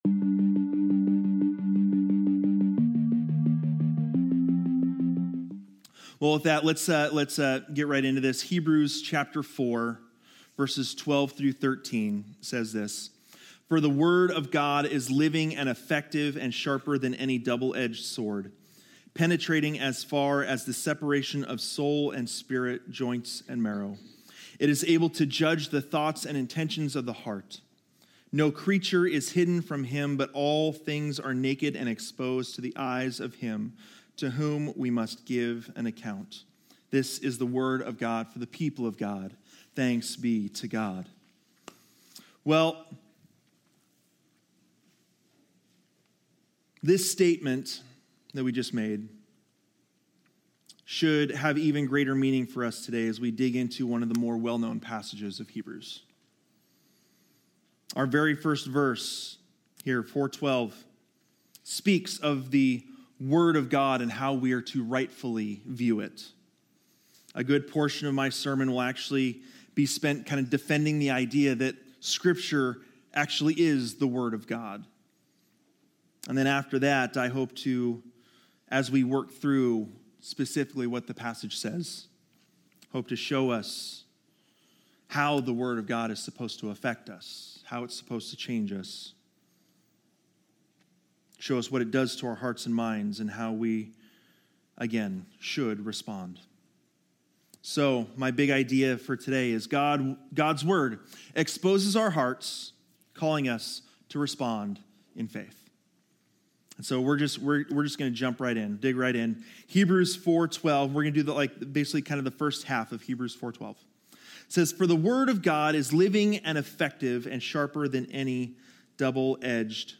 Sermons | Living Word Community Church